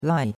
lai4.mp3